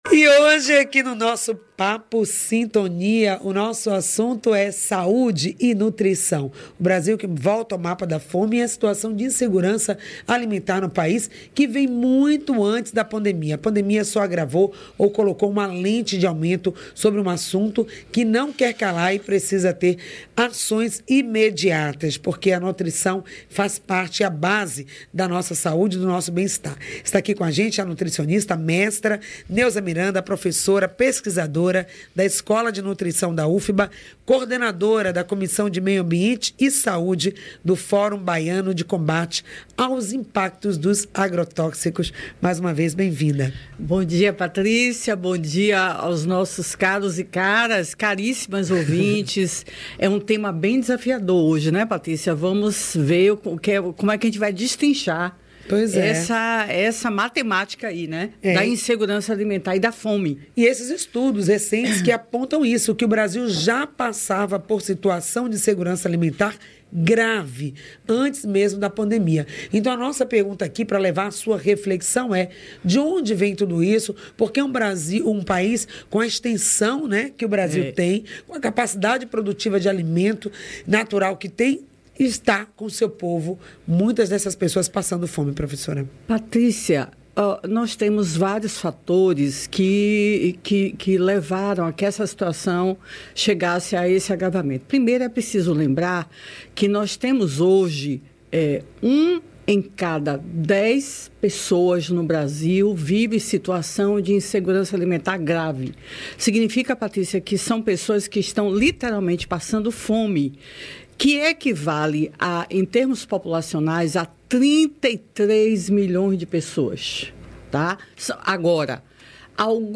Sobre o tema: Insegurança alimentar: causas e possíveis soluções. Ouça a entrevista: